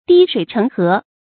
滴水成河 dī shuǐ chéng hé
滴水成河发音